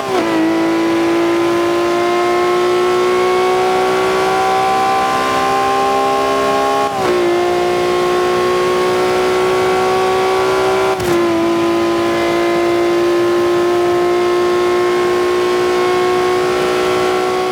Index of /server/sound/vehicles/lwcars/lam_reventon
fourth_cruise.wav